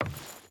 Wood Chain Walk 5.ogg